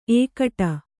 ♪ ēkaṭa